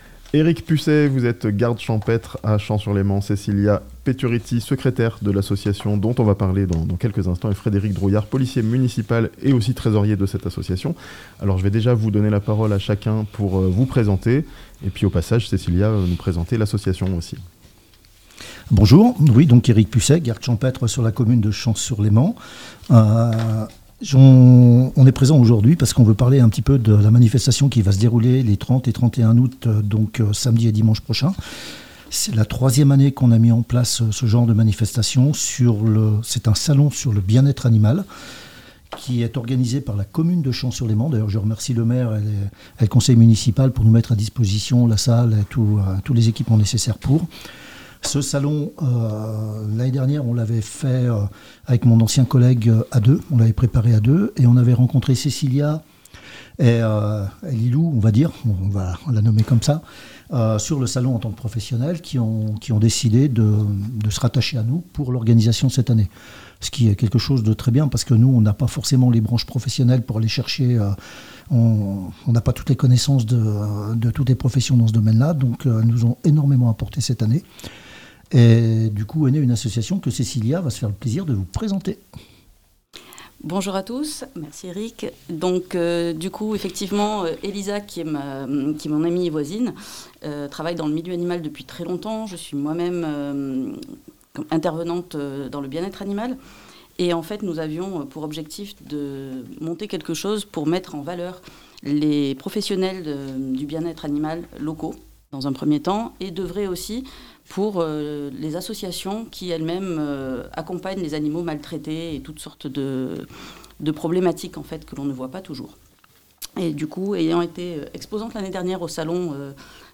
Un weekend autour du bien-être animal à Chens-sur-Léman (interview)